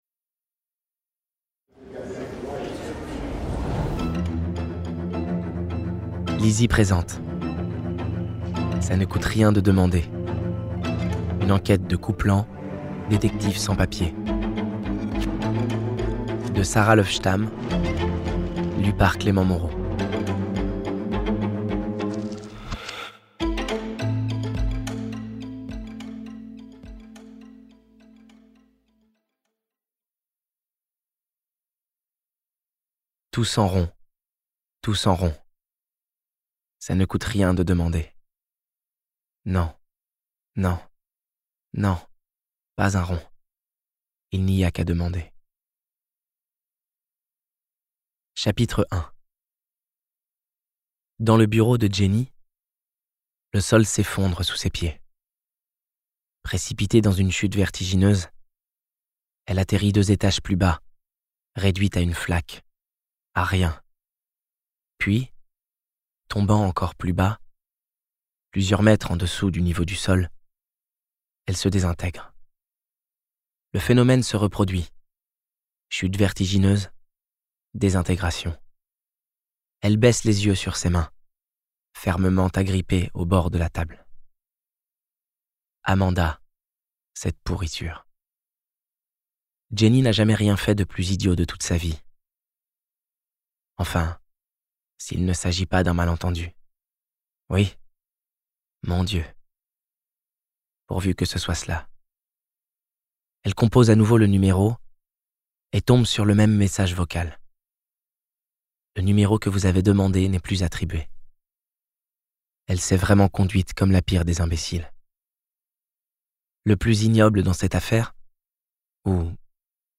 Extrait gratuit - Ça ne coûte rien de demander de Sara LÖVESTAM